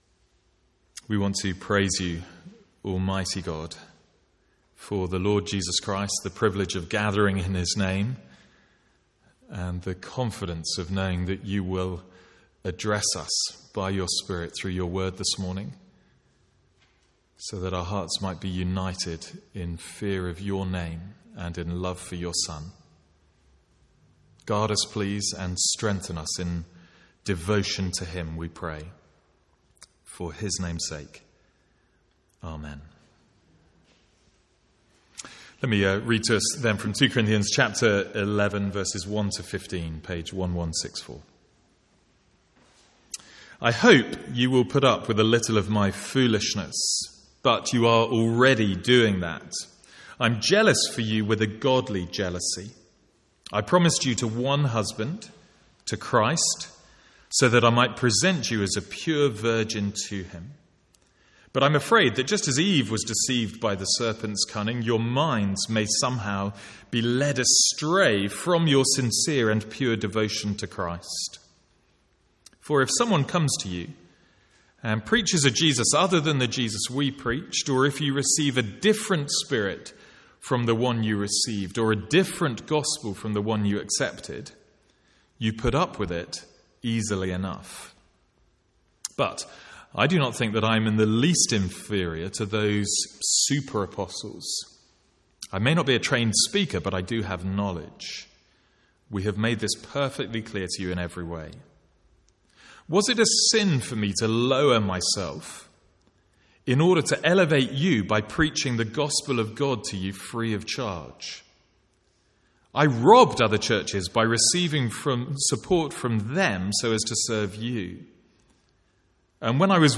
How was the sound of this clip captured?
From the Sunday morning series in 2 Corinthians.